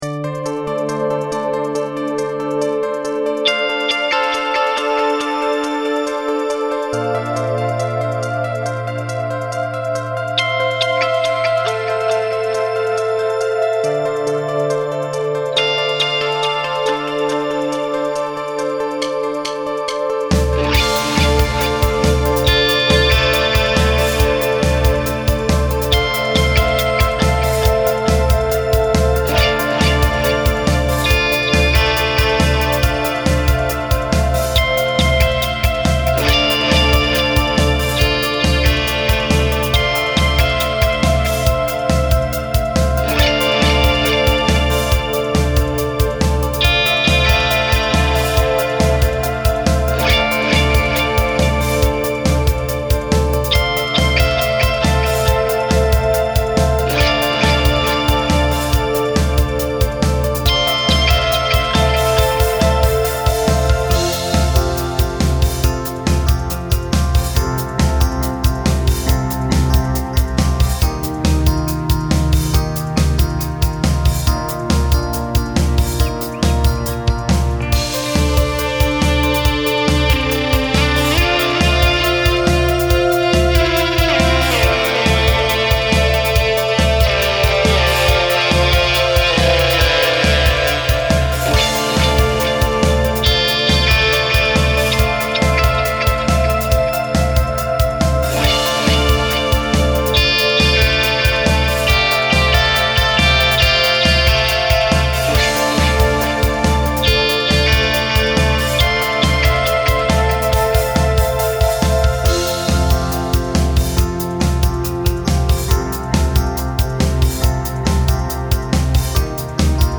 BPM : 139